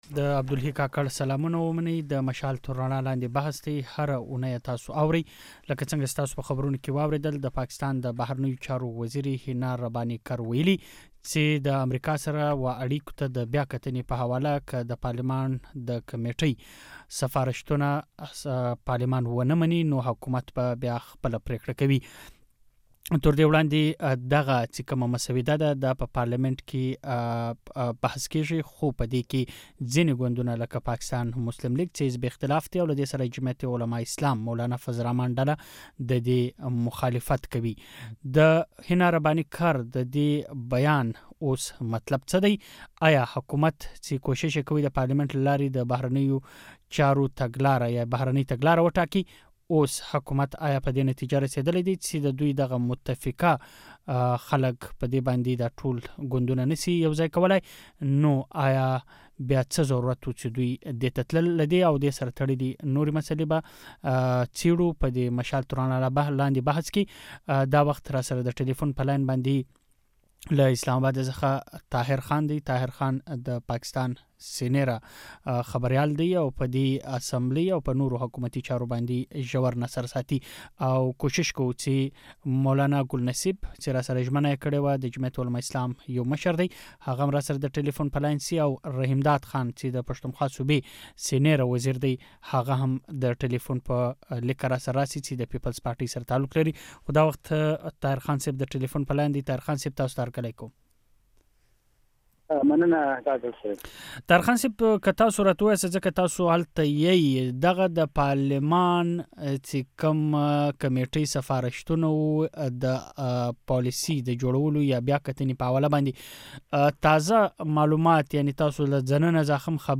دمشال تر رڼا لاندې بحث